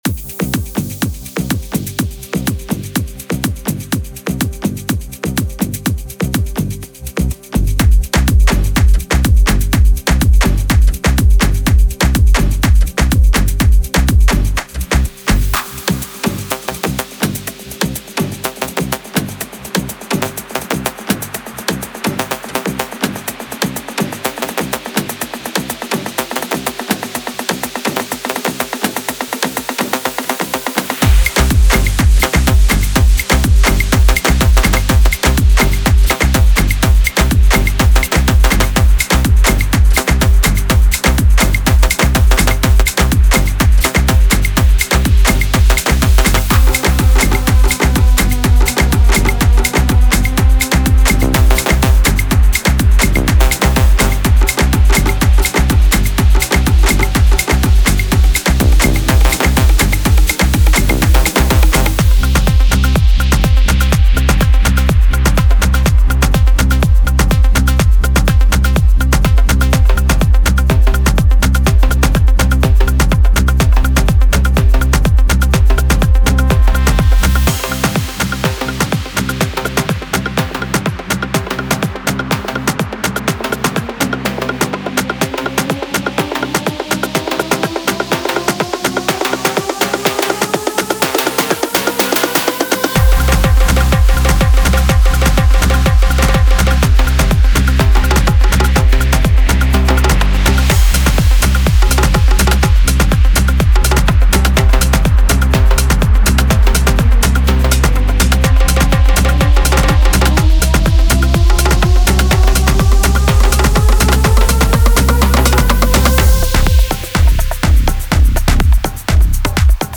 Genre:Afro House
デモサウンドはコチラ↓